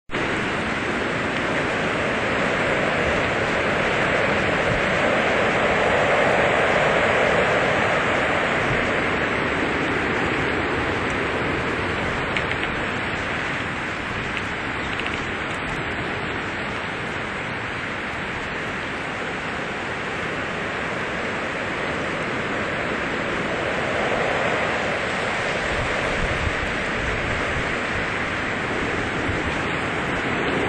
When the snowstorm clears, the wind turns direction and blows straight from the North Pole.
More winter wind 1 |
winterwind3.WMA